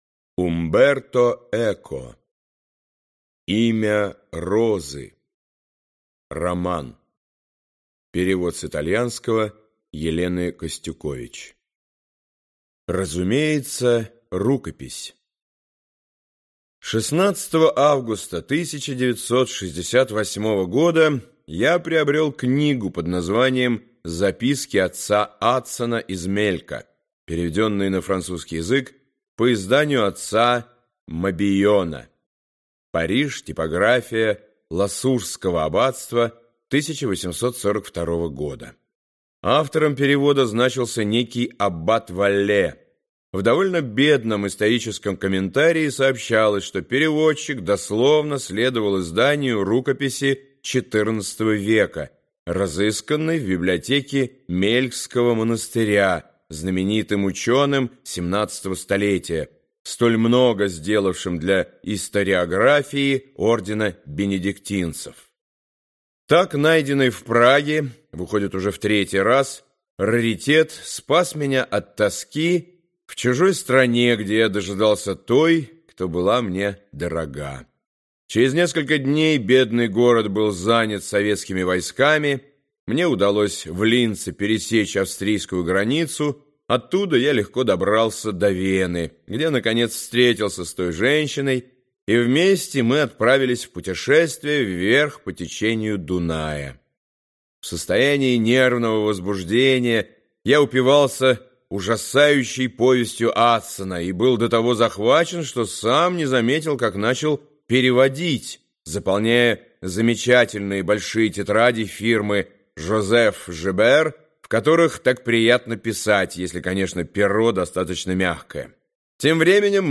Аудиокнига Имя розы - купить, скачать и слушать онлайн | КнигоПоиск